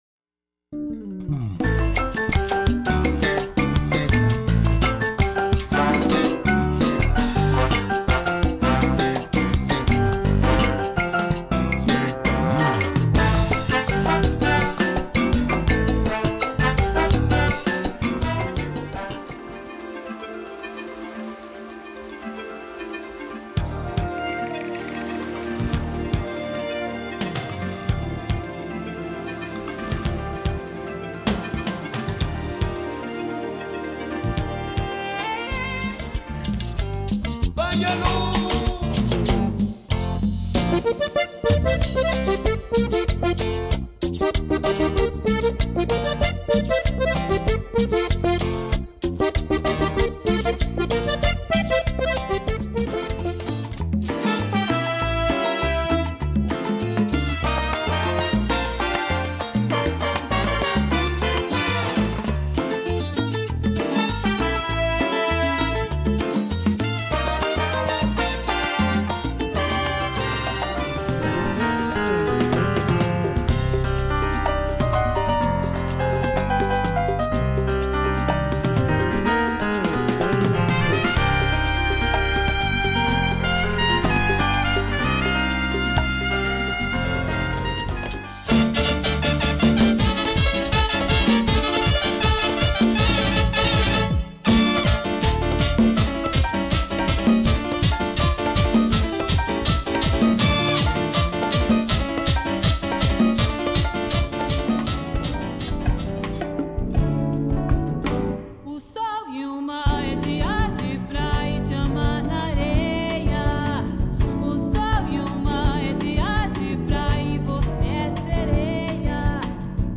Latin Dance Collection
HOT Latin music at it's best!!!